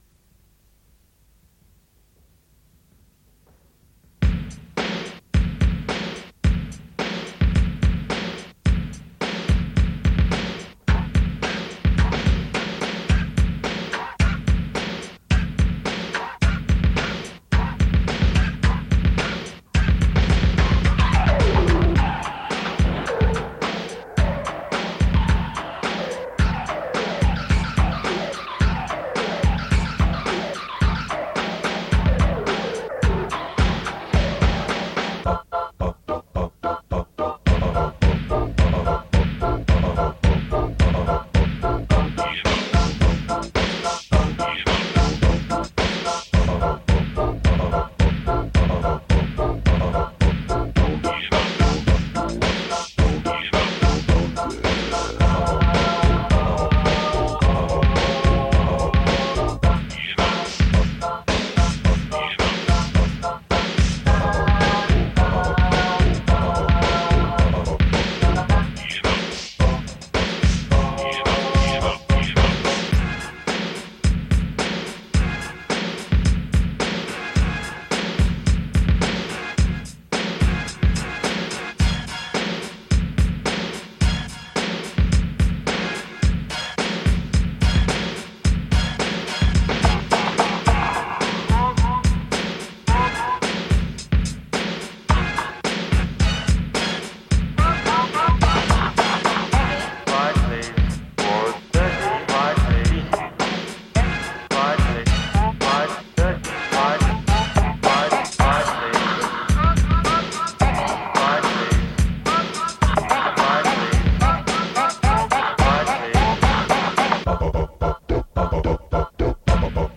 Instrumental Hip-Hop